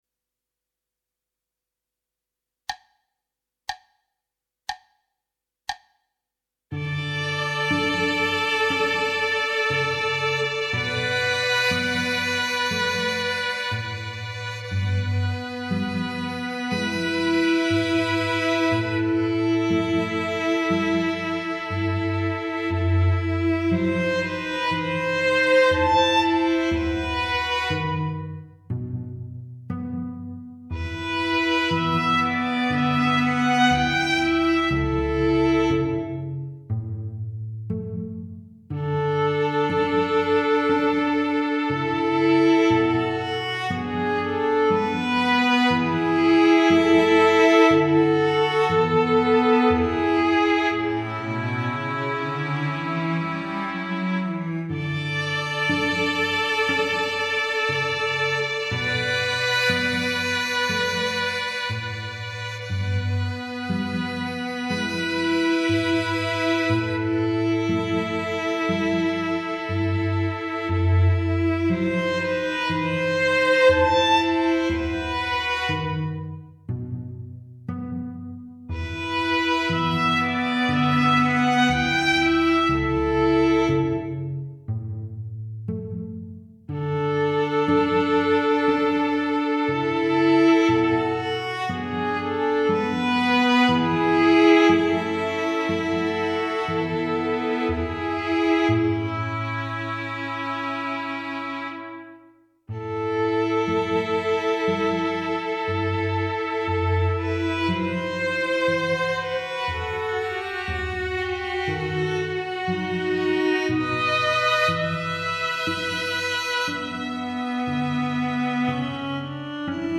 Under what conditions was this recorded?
Digital Orchestration